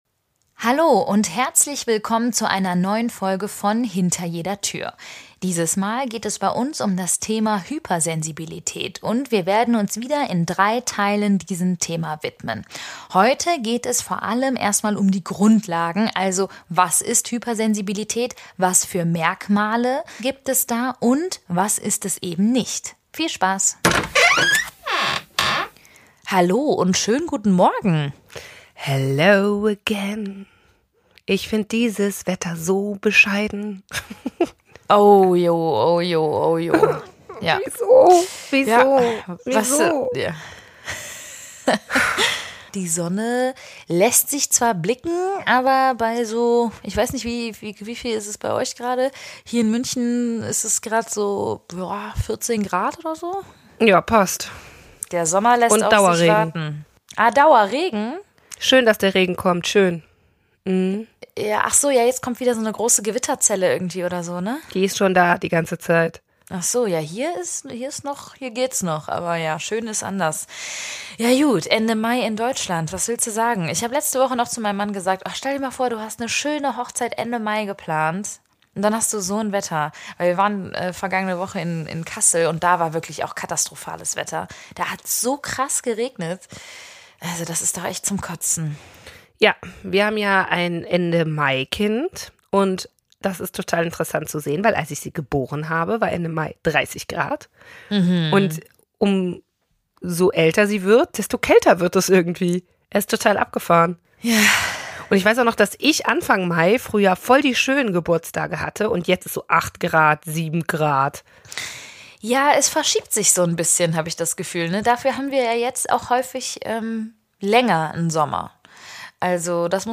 Hinter Jeder Tür - der neue Edutainment-Podcast.